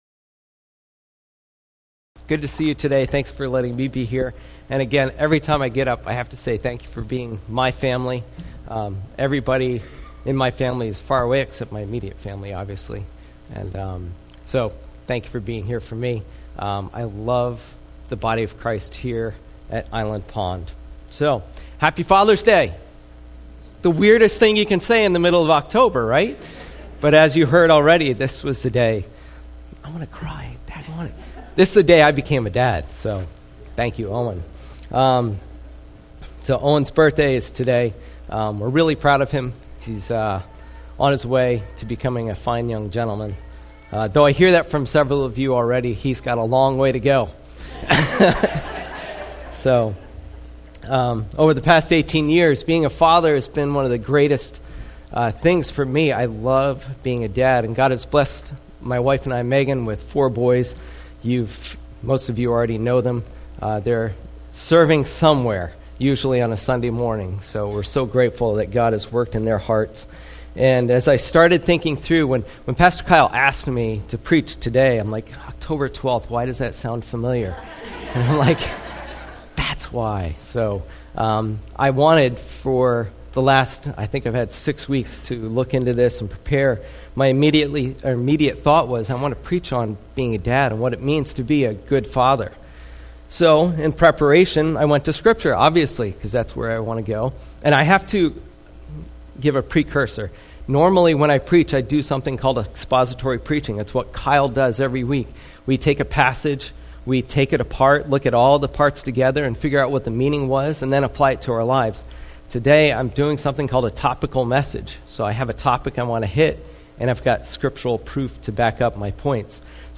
sermon
Island Pond Baptist Church is an SBC church in Hampstead, NH, just minutes from Atkinson, NH.